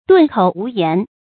頓口無言 注音： ㄉㄨㄣˋ ㄎㄡˇ ㄨˊ ㄧㄢˊ 讀音讀法： 意思解釋： 張口結舌，說不出話。